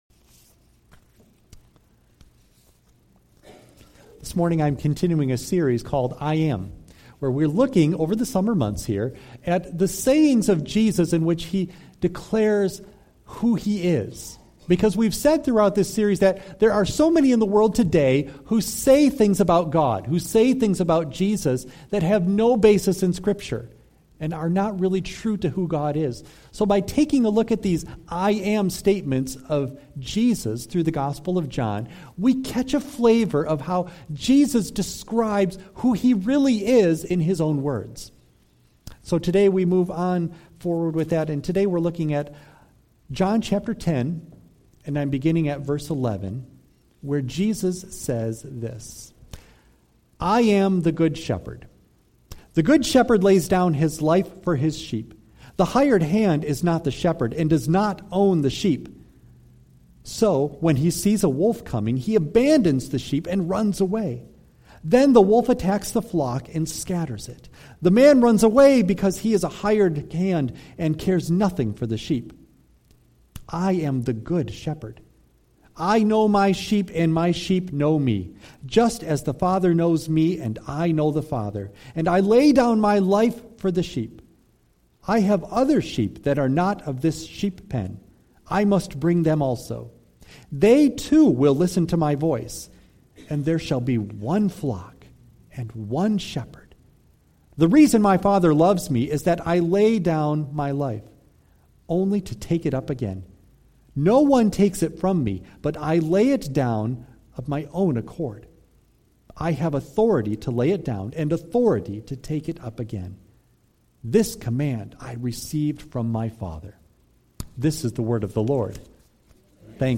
John 10:10-18 Service Type: Sunday AM Bible Text